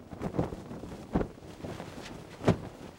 cloth_sail10.L.wav